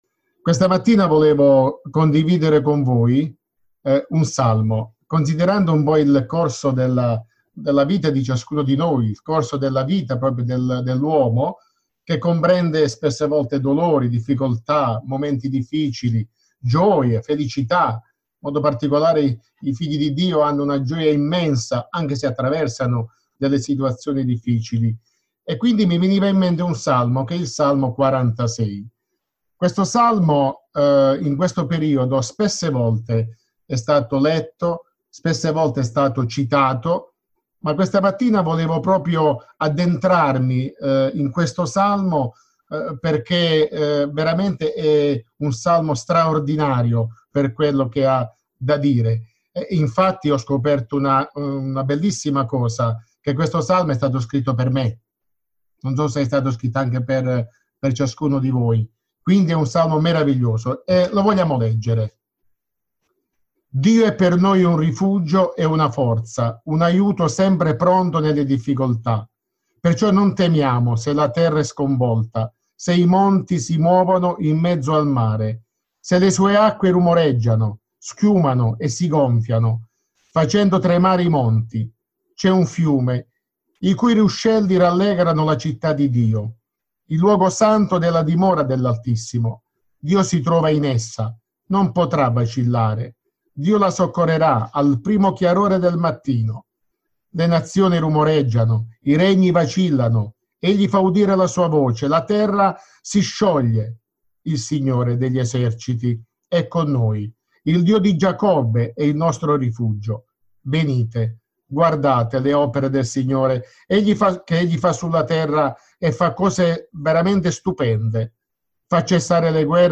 Bible Text: Salmo 46 | Preacher